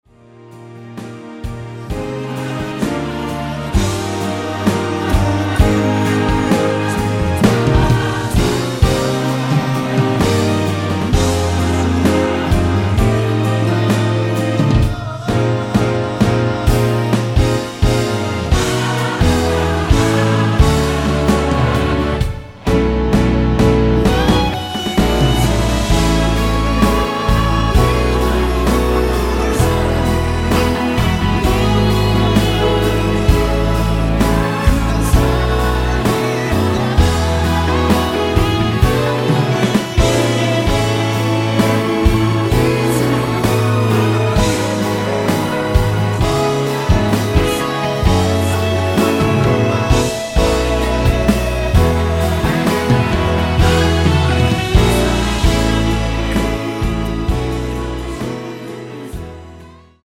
원키 코러스 포함된 MR 입니다.(미리듣기 참조)
Eb
앞부분30초, 뒷부분30초씩 편집해서 올려 드리고 있습니다.
중간에 음이 끈어지고 다시 나오는 이유는